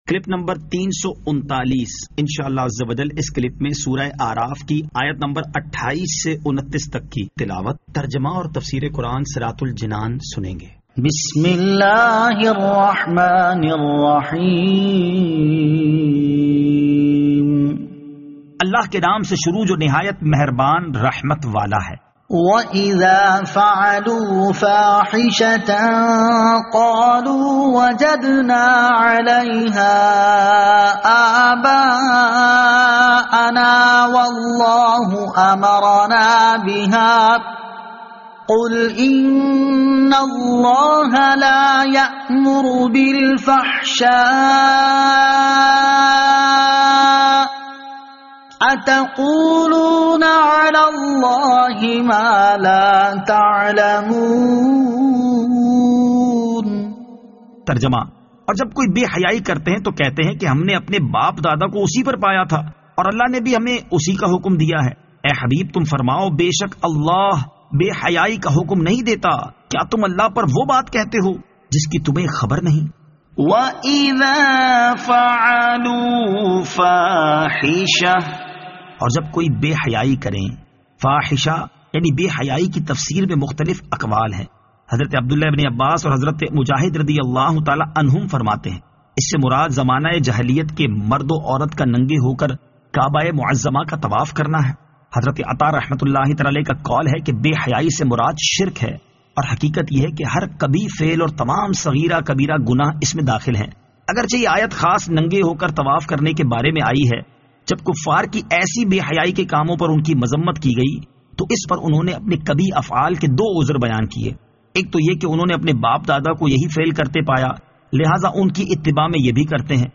Surah Al-A'raf Ayat 28 To 29 Tilawat , Tarjama , Tafseer